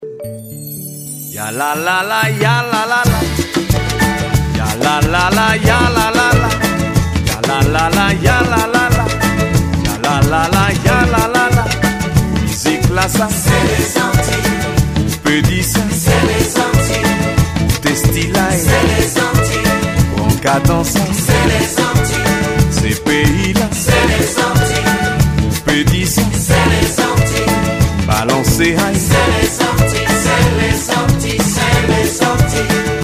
Piano
Percussions